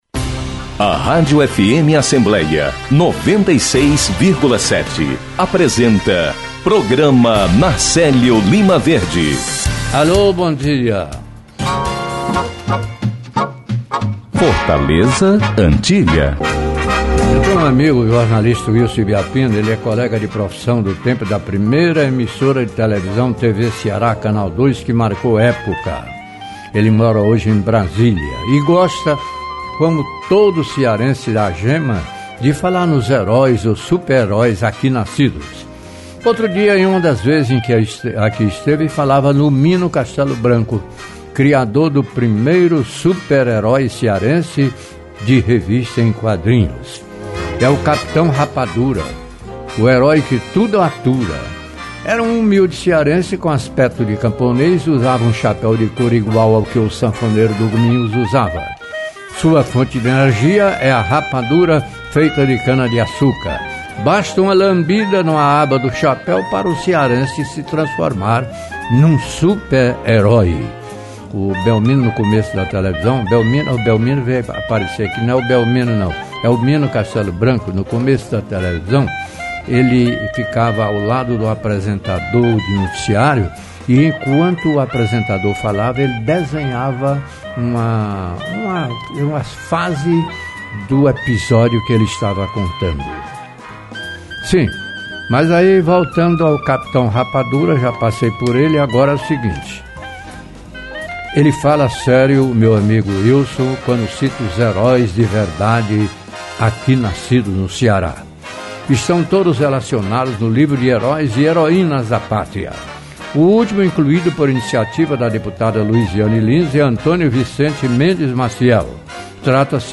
O primeiro vice-presidente da Assembleia Legislativa, deputado Fernando Santana (PT), é o entrevistado